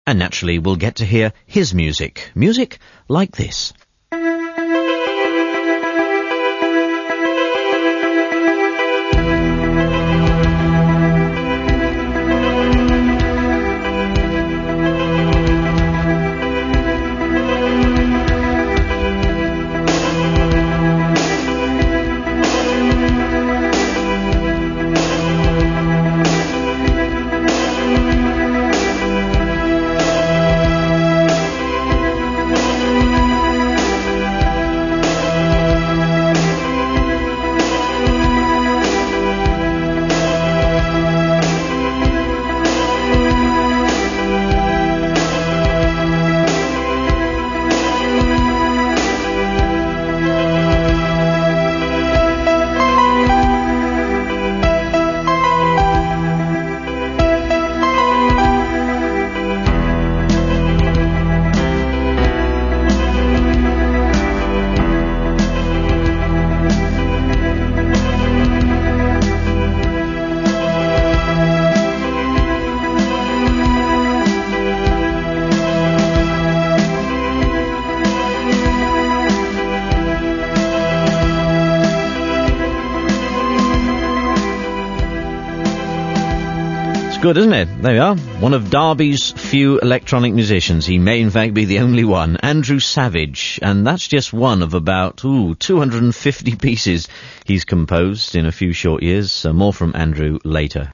This is a brief extract from my BBC Radio Derby interview, which was